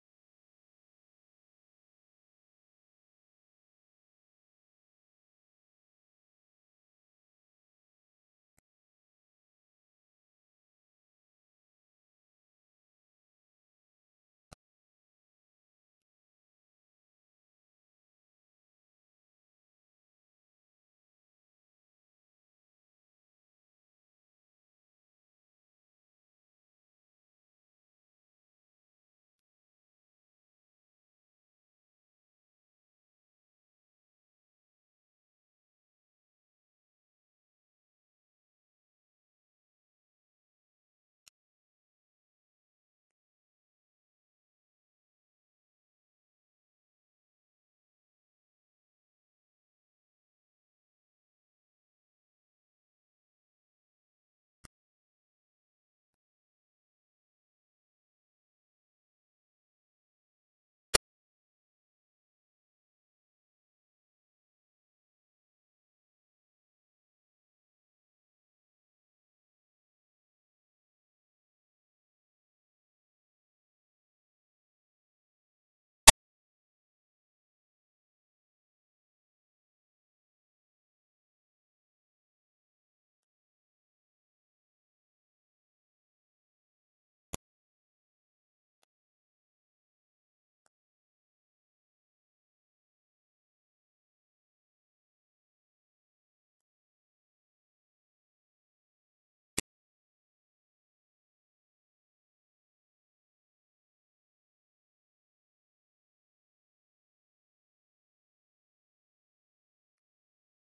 neutre - guitare - bluesy - cosy - chaleureux